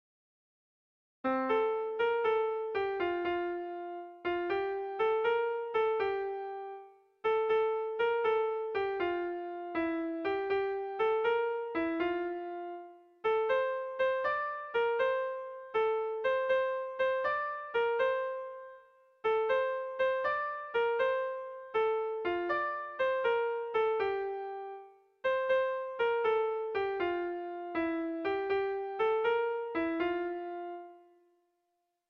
Sentimenduzkoa
Hamarreko txikia (hg) / Bost puntuko txikia (ip)
A1A2B1B2A2